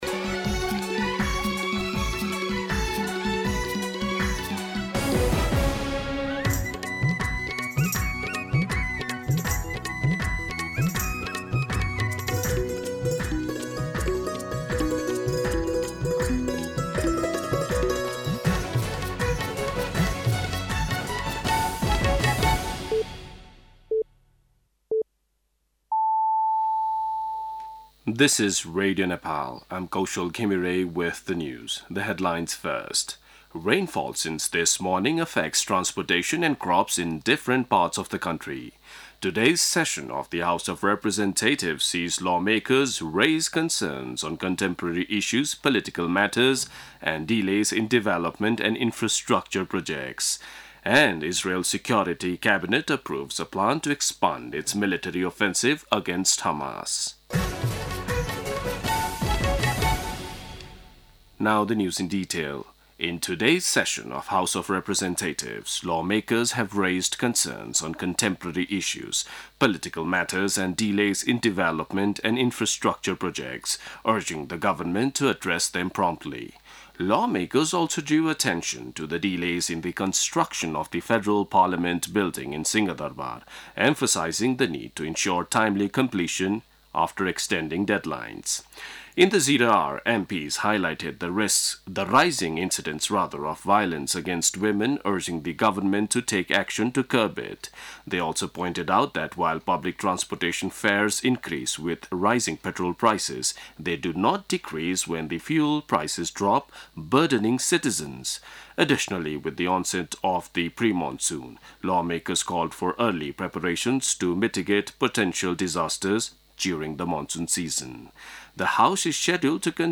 दिउँसो २ बजेको अङ्ग्रेजी समाचार : २३ वैशाख , २०८२
2pm-English-News-23.mp3